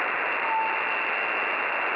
При проведении MS связи снова в 2008 г. прозвучал странный секундный бип(первый-прошл.год